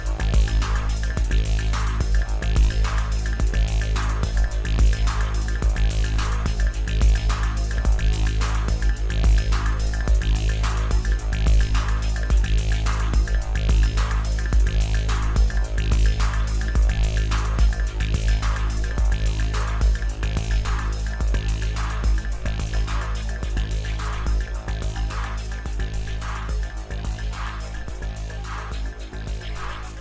a música aqui é alienígena e minimalista